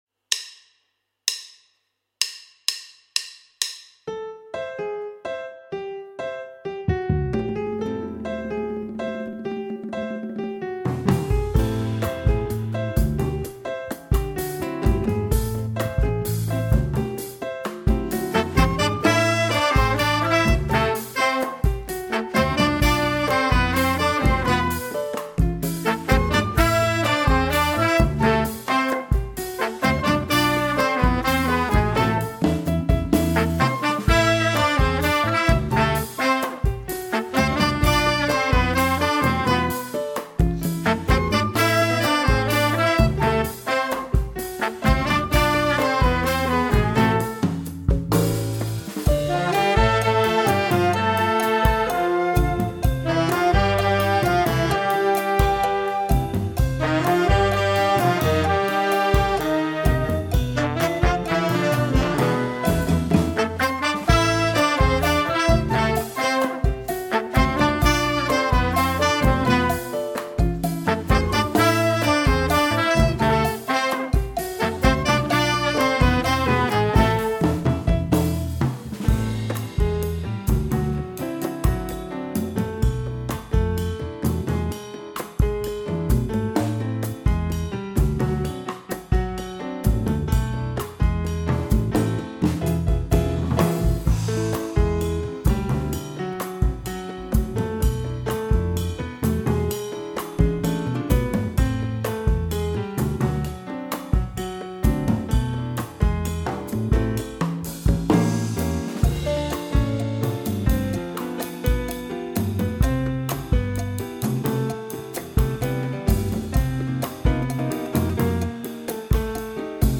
Voici 3 titres originaux enregistrés « à distance » par les profs ainsi que des bandes son qui vous permettrons de « virer » un prof et de prendre la place!
Thème sans solos :
salcha-avec-theme-sans-solos.mp3